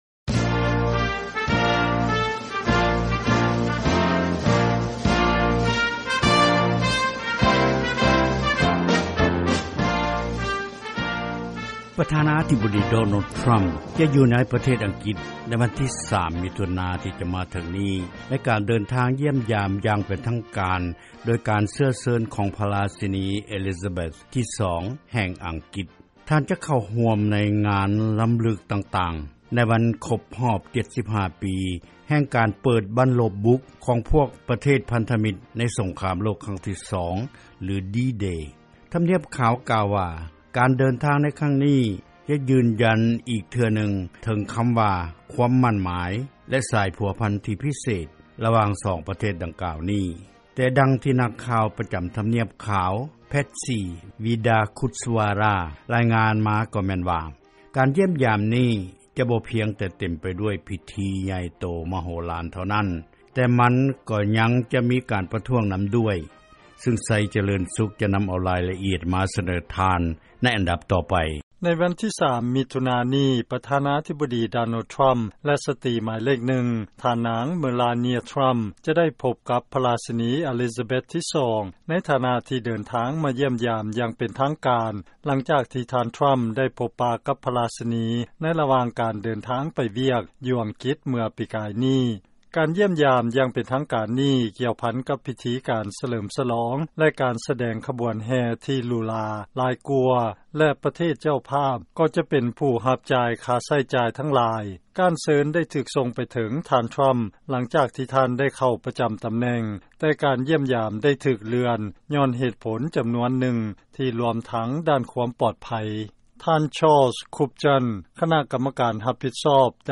ເຊີນຟັງລາຍງານ ການຢ້ຽມຢາມ ອັງກິດ ຢ່າງເປັນທາງການ ຂອງ ປ. ທຣຳ ຈະຖືກຕ້ອນຮັບ ໂດຍ ພິທີການຫຼູຫຼາ ພ້ອມດ້ວຍ ການປະທ້ວງ